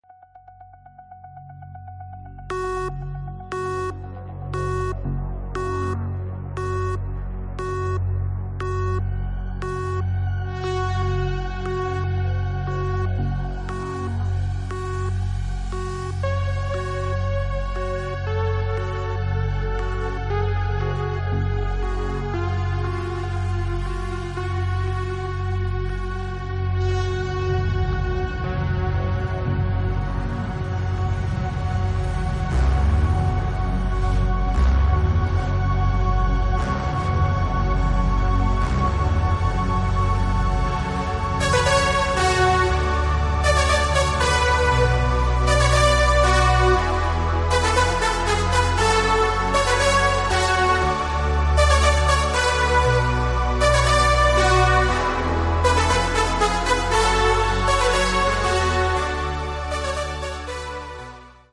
mixed & remixed by various DJs